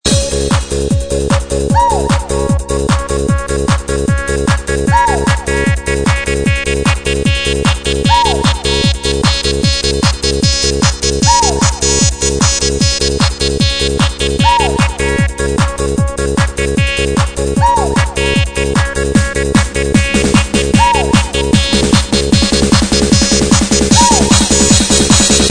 что на неё есть несколько клубных ремиксов